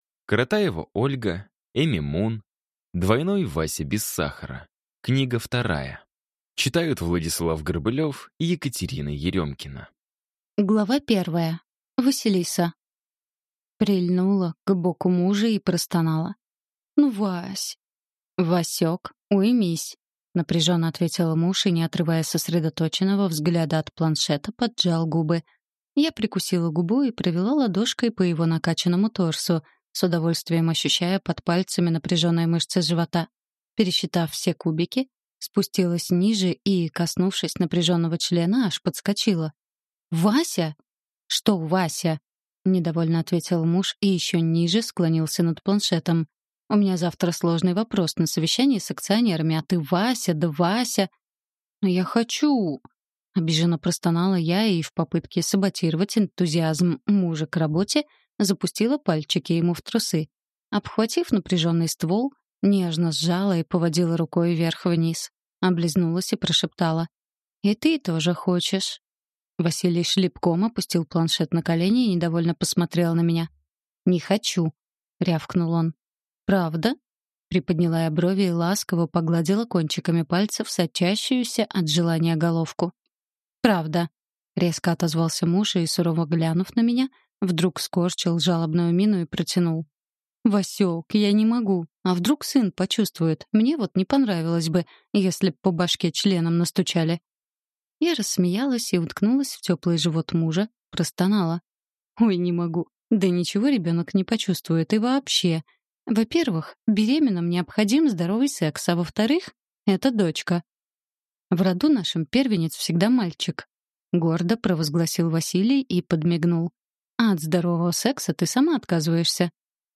Аудиокнига Двойной Вася без сахара – 2 | Библиотека аудиокниг